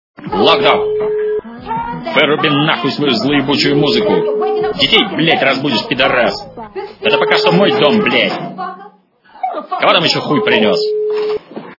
При прослушивании х/ф Не грози Южному Централу. - Выруби наф... свою зло е...ю музыку качество понижено и присутствуют гудки.